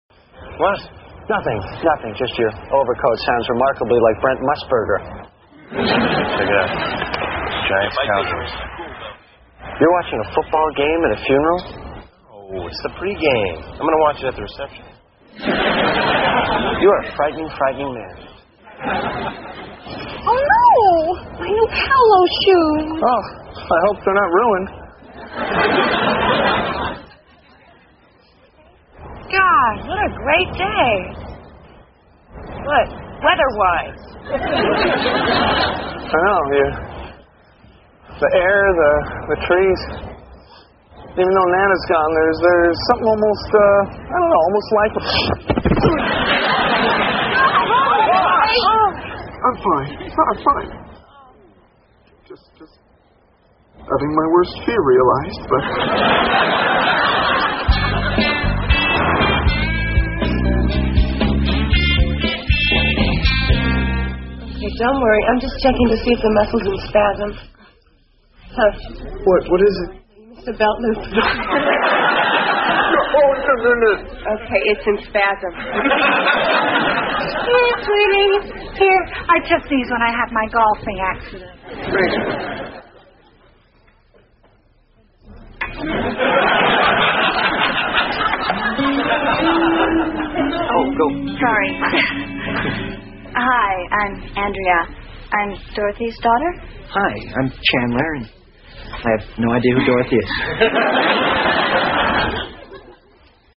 在线英语听力室老友记精校版第1季 第95期:祖母死了两回(9)的听力文件下载, 《老友记精校版》是美国乃至全世界最受欢迎的情景喜剧，一共拍摄了10季，以其幽默的对白和与现实生活的贴近吸引了无数的观众，精校版栏目搭配高音质音频与同步双语字幕，是练习提升英语听力水平，积累英语知识的好帮手。